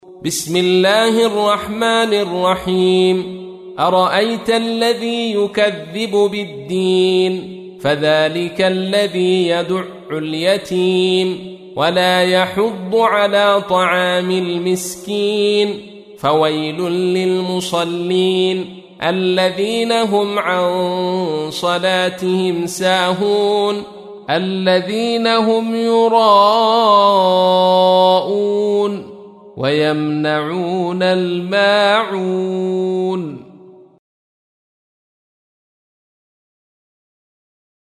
107. سورة الماعون / القارئ